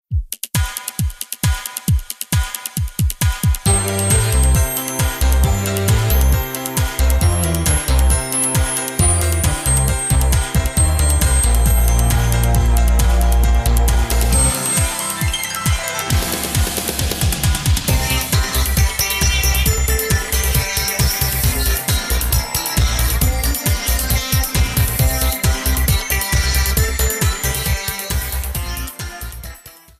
Trimmed and fadeout
Fair use music sample